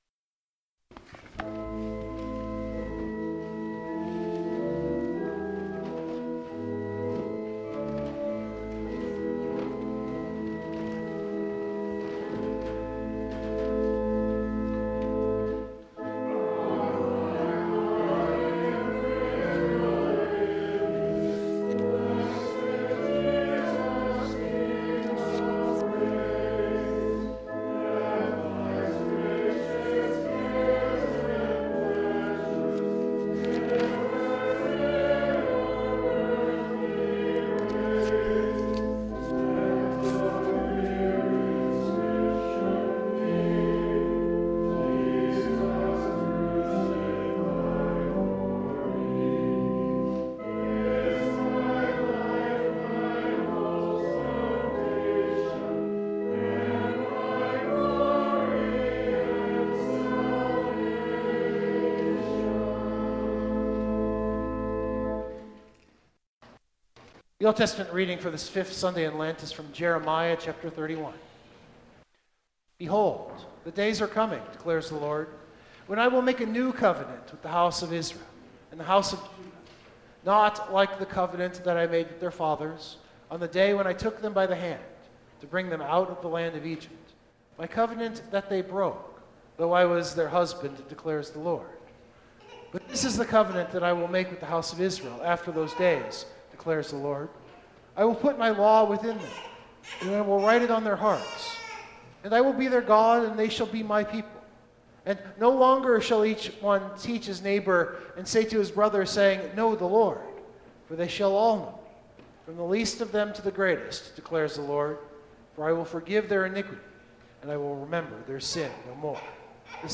The text is Jeremiah’s invoking of a new covenant. The sermon attempts to think about what we are talking about when we say the word covenant.